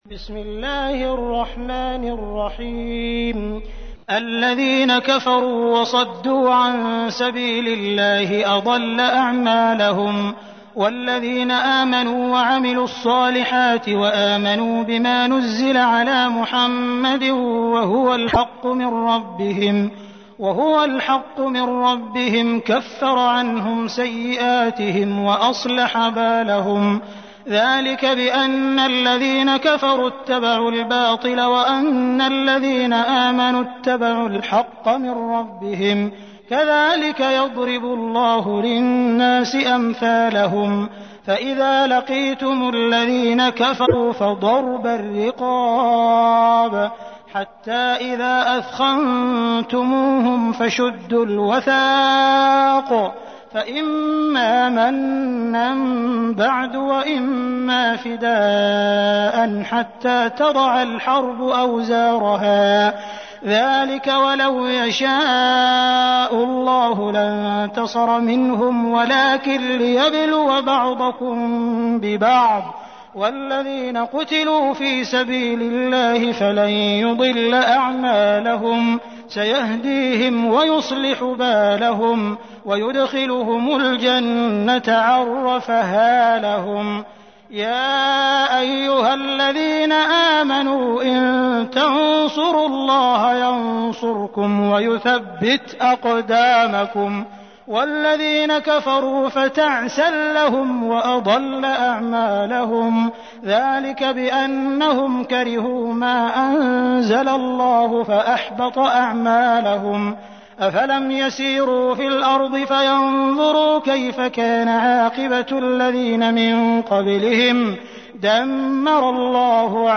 تحميل : 47. سورة محمد / القارئ عبد الرحمن السديس / القرآن الكريم / موقع يا حسين